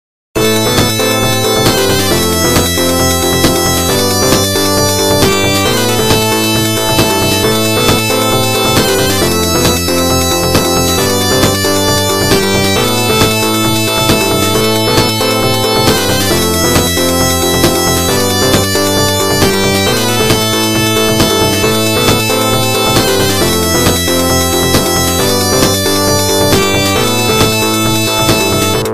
8 бит
Отличного качества, без посторонних шумов.